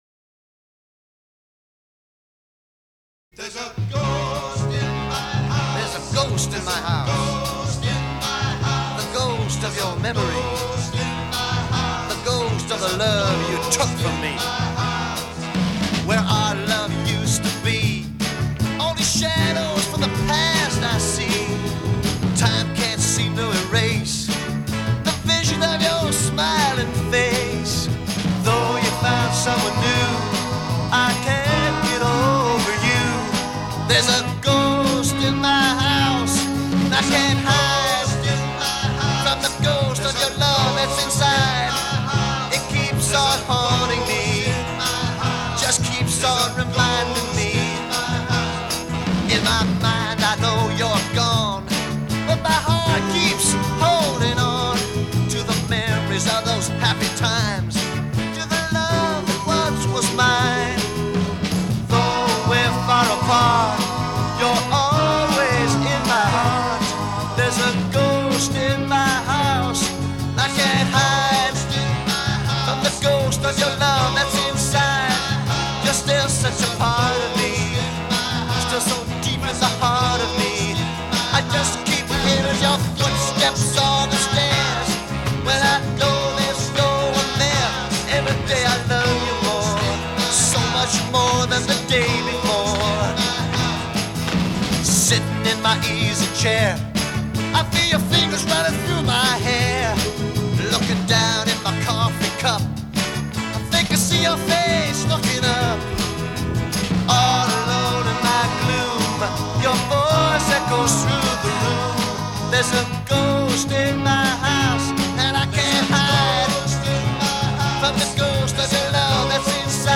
Genre: R&B – Soul – Mowtown.